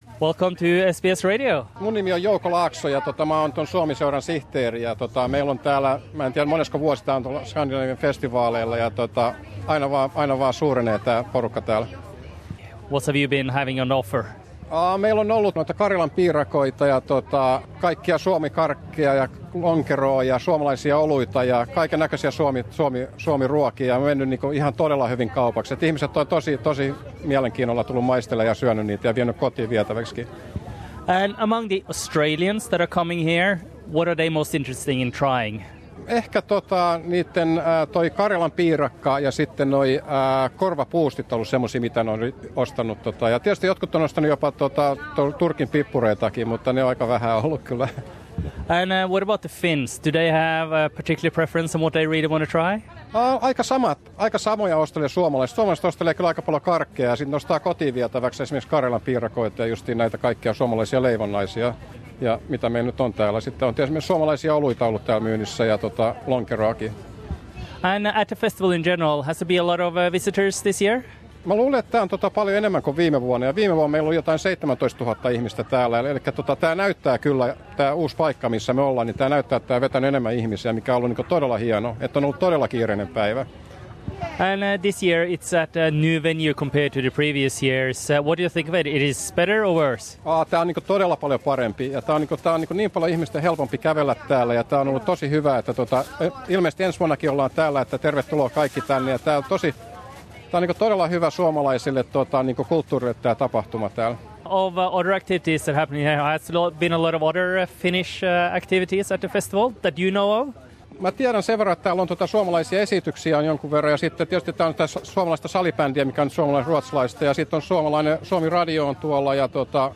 haastattelee